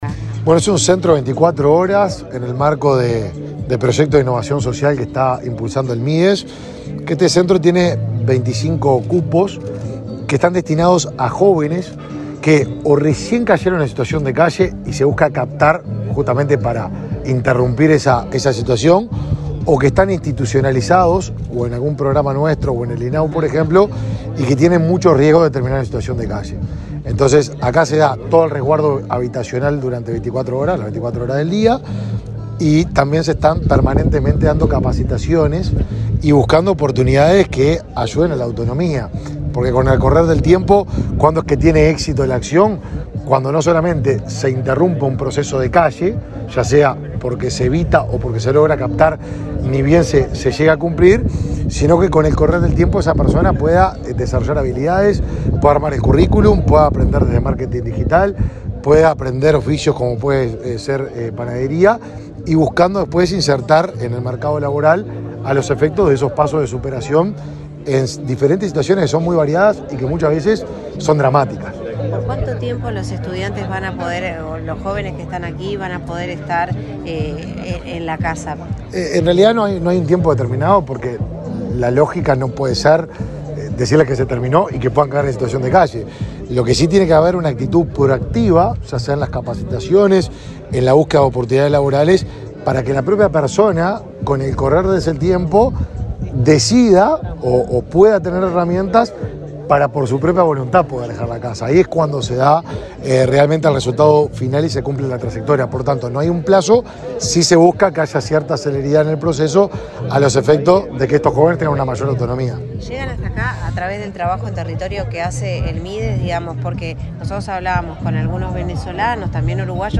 Declaraciones a la prensa del ministro de Desarrollo Social, Martín Lema
El titular de la cartera, Martín Lema, explicó a la prensa las características del servicio.